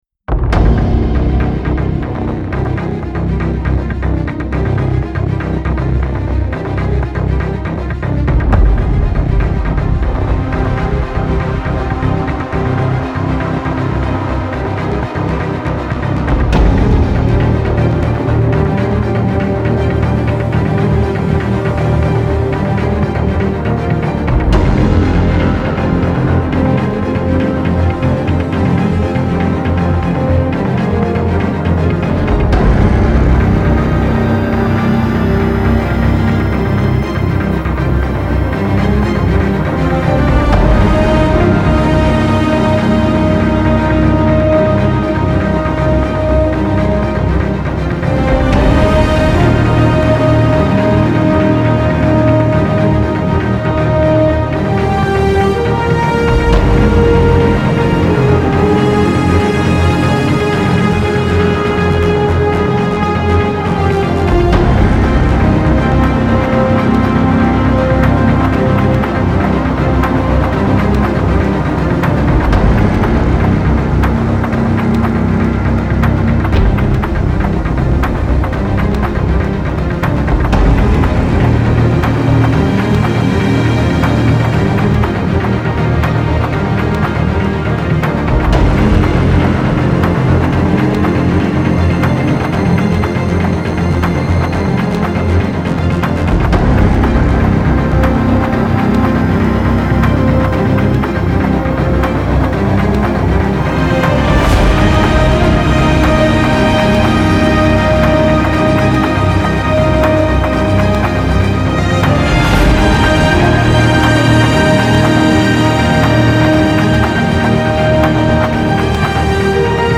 Arising (epic/orchestral
mp3 320 kbs длительность 2:48 стиль: оркестрово-эпичное Как то меня проперло после нескольких вечеров за игрой в шутеры (в частности, вчера в первый раз...